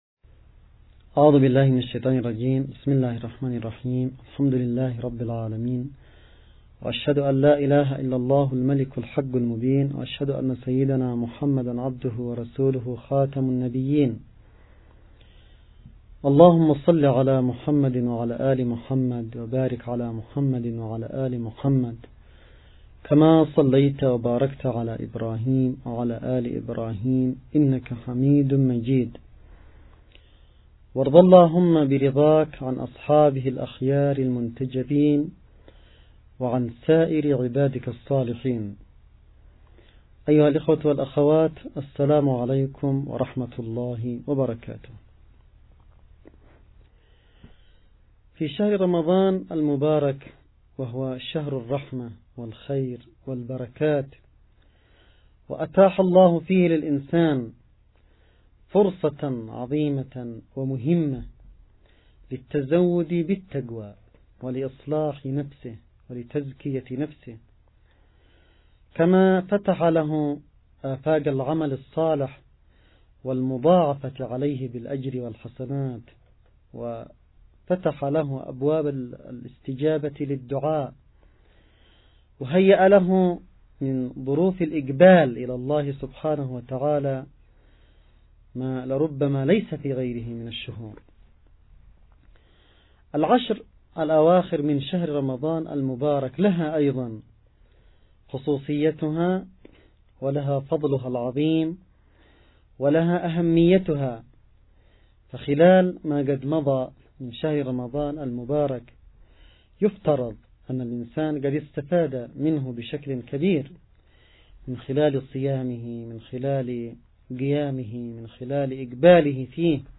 محاضرة_ليالي_القدر_المحاضرة_الرمضانية.mp3